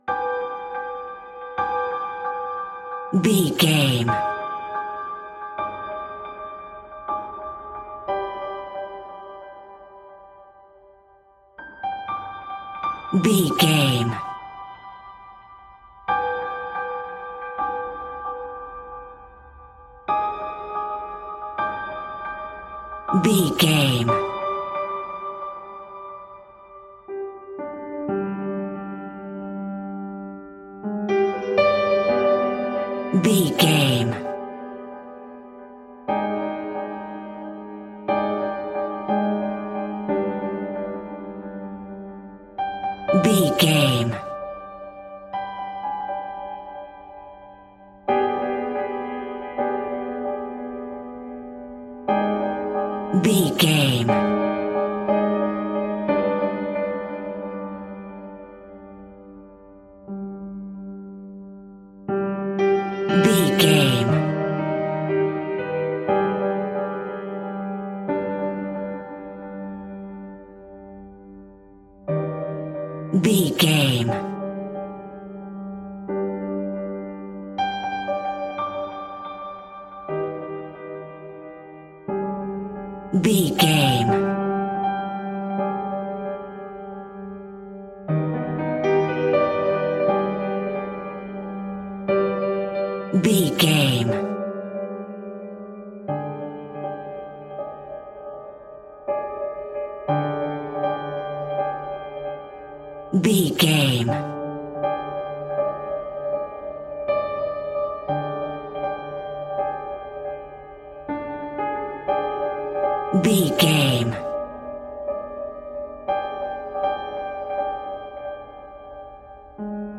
Nightmare Piano Sounds.
Diminished
tension
ominous
eerie
horror piano